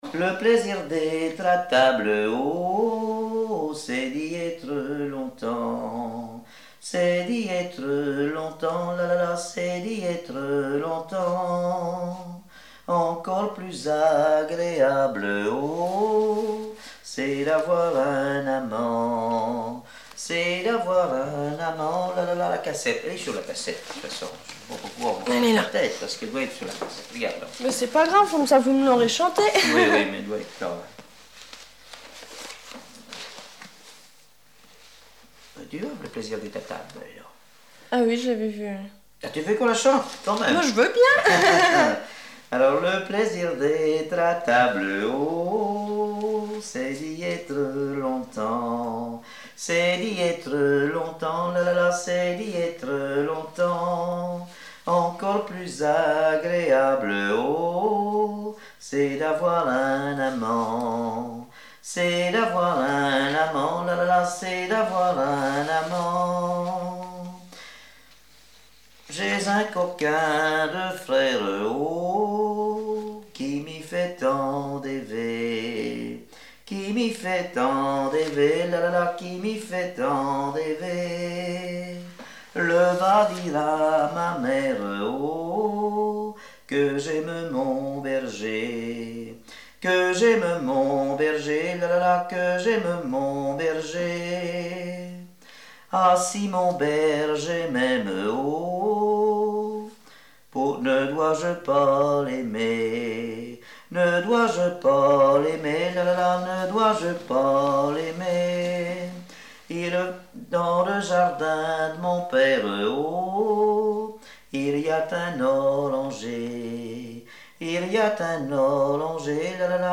Mémoires et Patrimoines vivants - RaddO est une base de données d'archives iconographiques et sonores.
Répertoire de chansons traditionnelles et populaires
Pièce musicale inédite